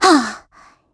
Gremory-Vox_Attack2_kr.wav